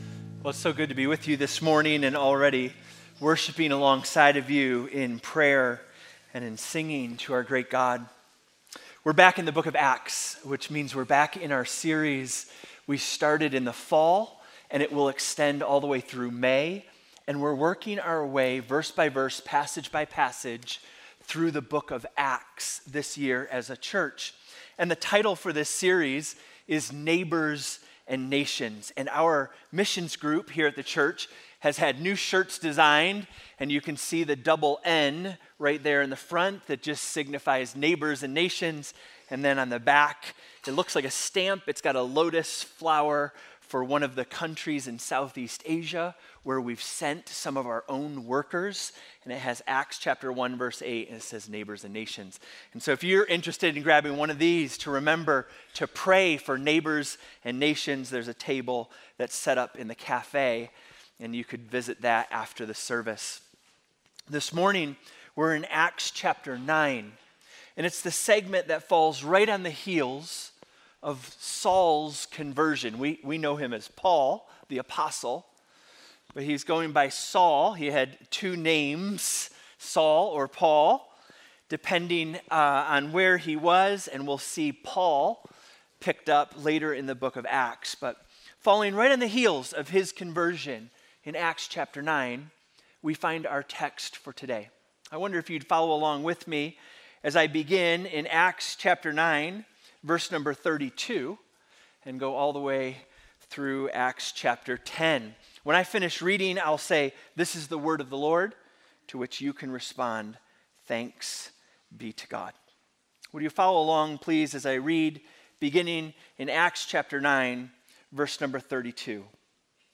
Gospel Grace Church Sermon Audio